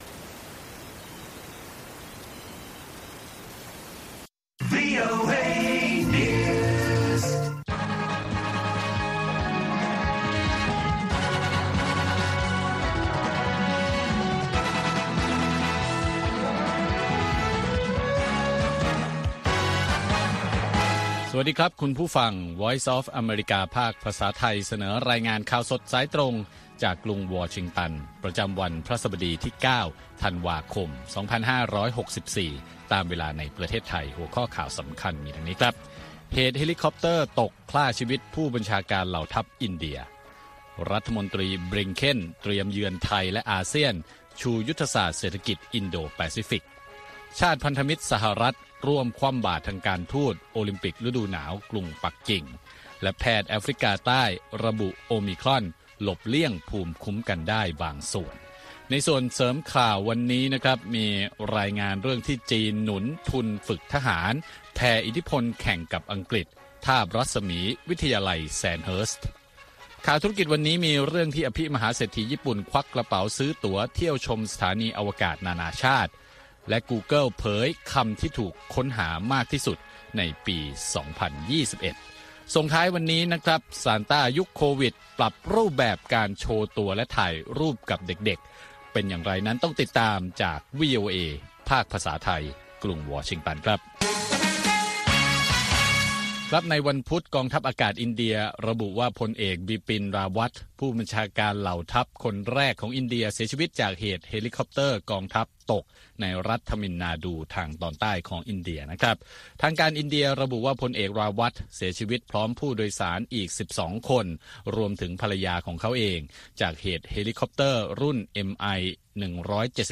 ข่าวสดสายตรงจากวีโอเอ ภาคภาษาไทย 6:30 – 7:00 น. ประจำวันพฤหัสบดีที่ 9 ธันวาคม2564 ตามเวลาในประเทศไทย